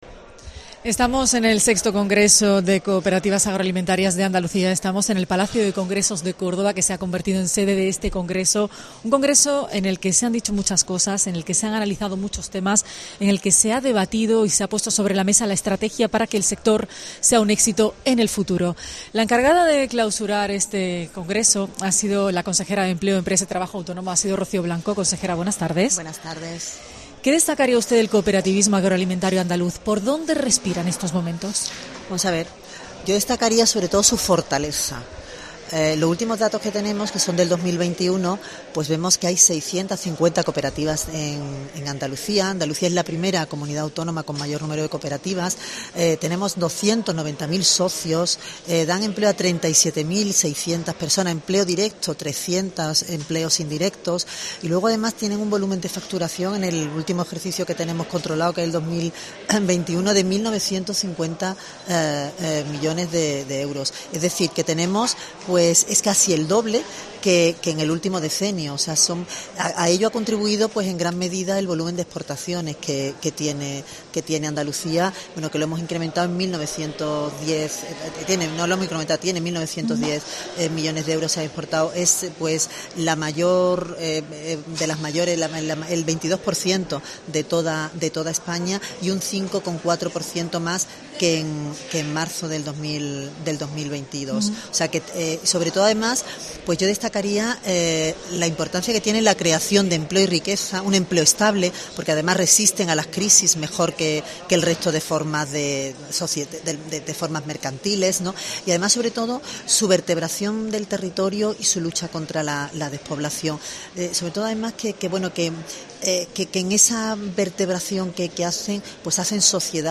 La Consejera de Empleo, Empresa y Trabajo Autónomo ha mostrado el apoyo del gobierno andaluz al sector en la clausura del VI Congreso de Cooperativas Agroalimentarias de Andalucía
Rocío Blanco, ha atendido a los micrófonos de COPE Andalucía, donde ha querido destacar la gran fortaleza del sector.